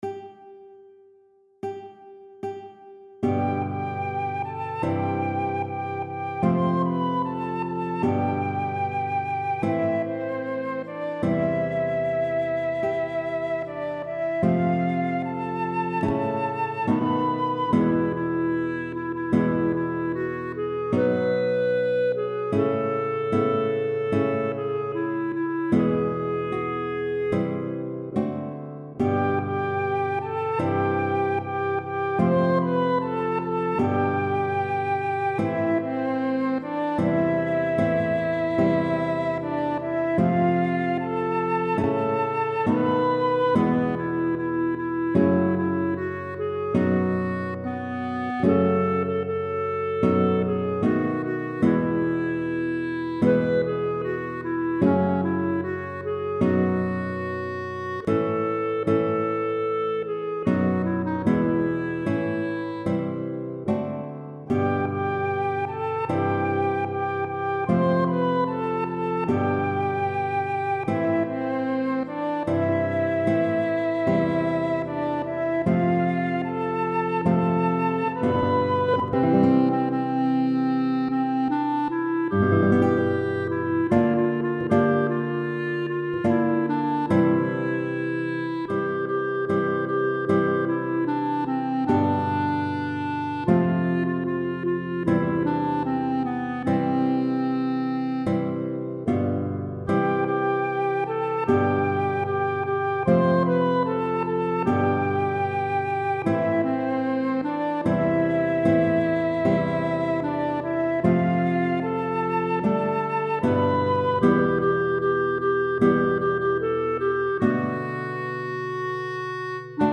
Computer audio with clarinet on verses
and flute (8va) on antiphon: